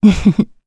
Hilda-Vox_Happy1.wav